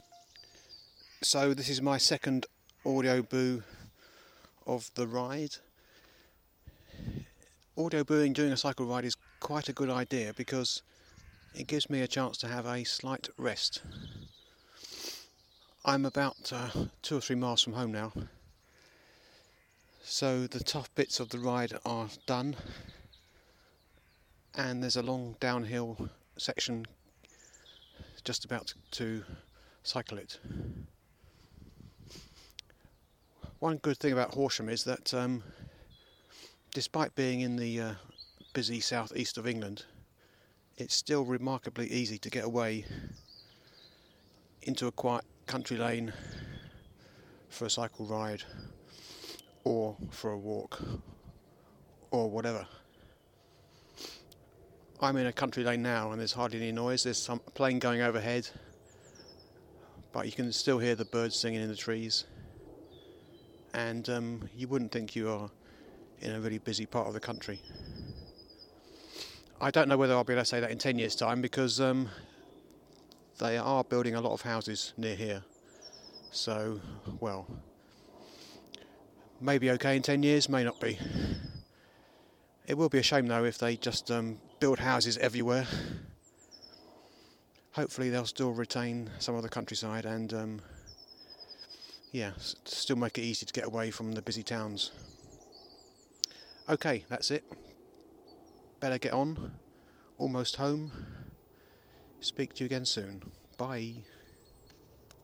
Cycle ride boo - almost home